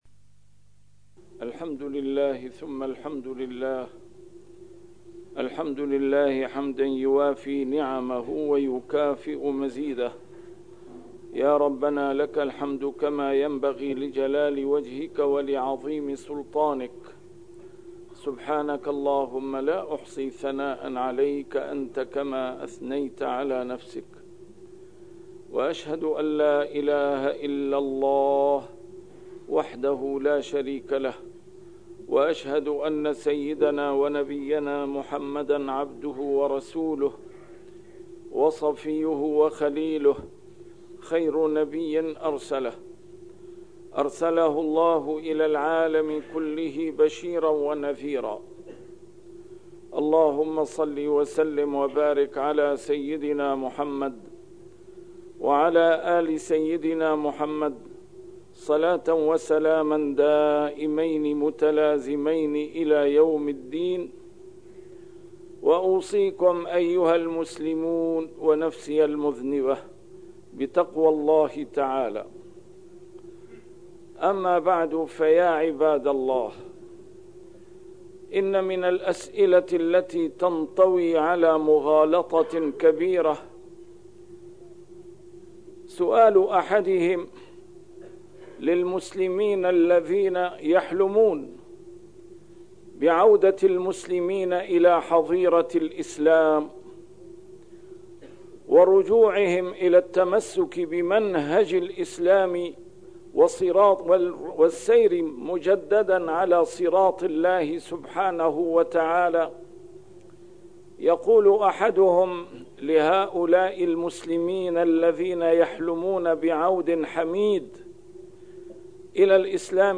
A MARTYR SCHOLAR: IMAM MUHAMMAD SAEED RAMADAN AL-BOUTI - الخطب - هذه مشكلاتنا .. حقائق وحلول